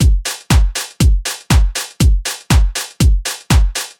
hab gerade noch ein tool ausprobiert, das kostenlos ist und ideal zum entharschen:
sTilt is a free linear/natural phase and IIR filter which tilts the audio spectrum around a given center frequency.